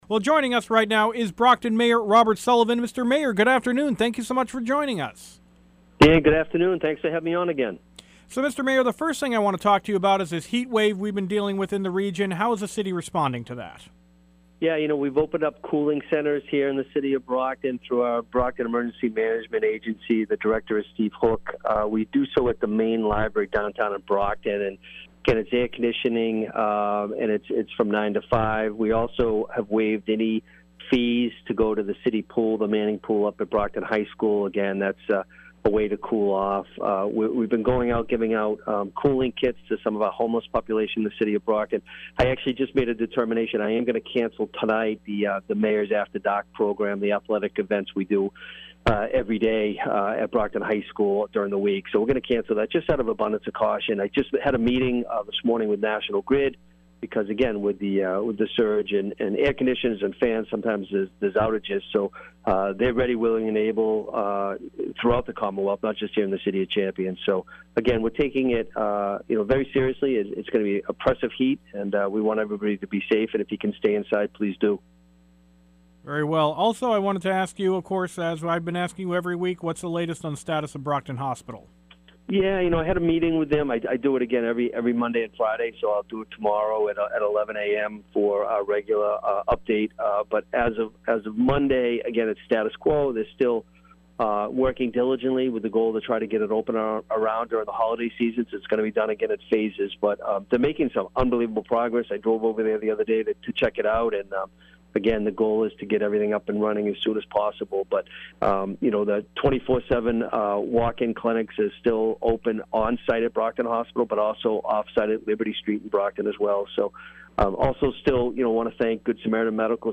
Brockton Mayor Robert Sullivan speaks